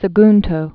(sə-gntō, sä-)